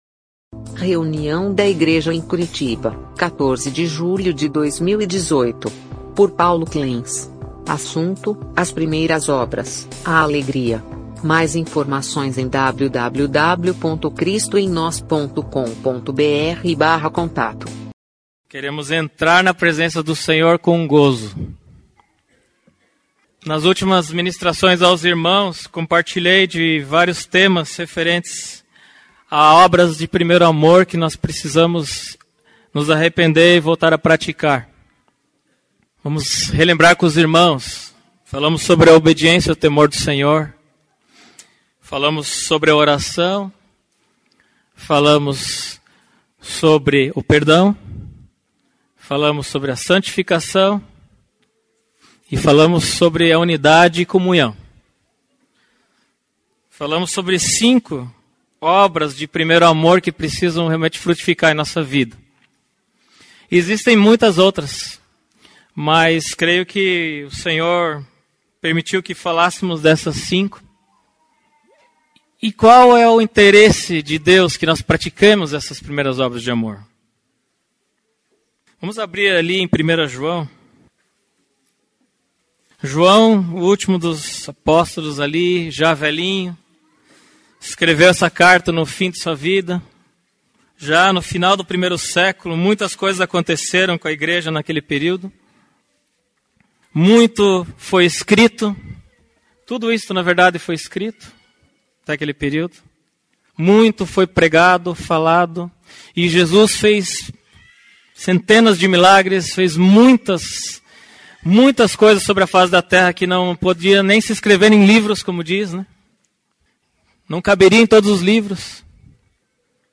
Mensagem compartilhada
na reunião da igreja em Curitiba